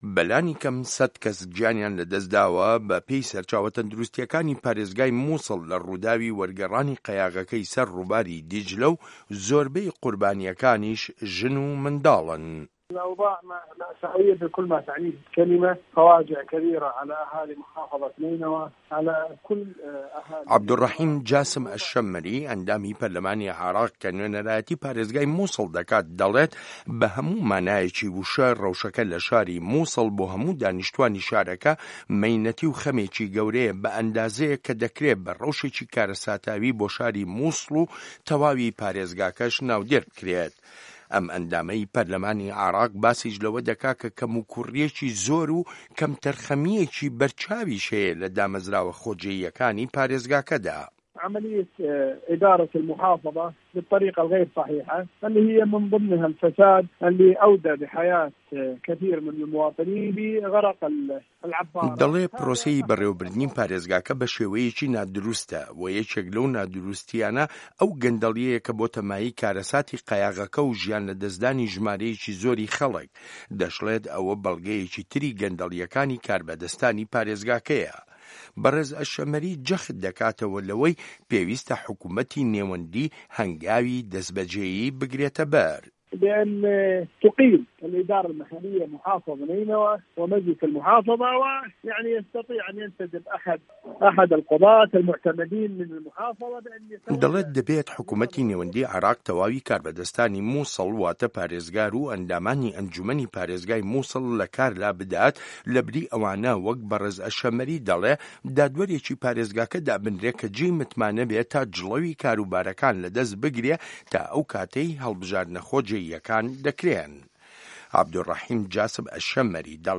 ڕاپۆرت لەسەر بنچینەی لێدوانەکانی عەبدولڕەحیم جاسم ئەلشەممەری